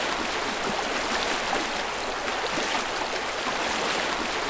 waterfall_loop.wav